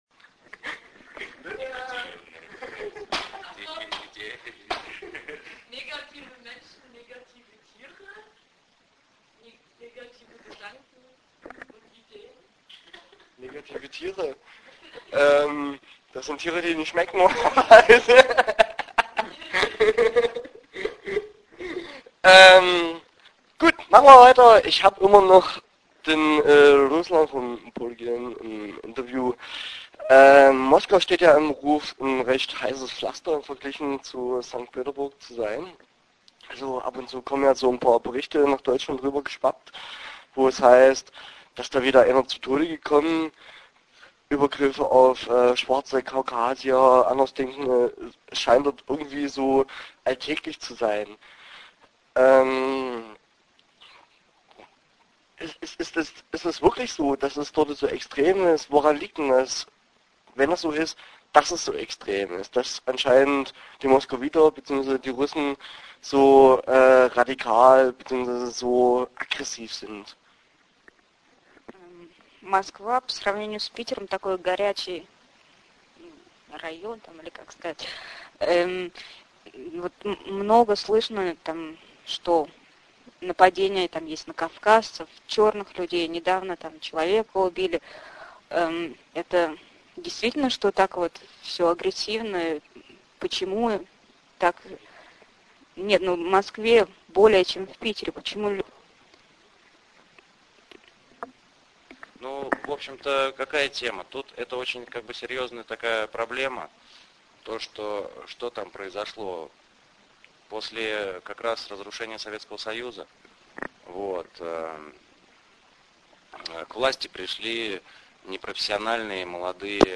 Interview Teil 1 (20:32)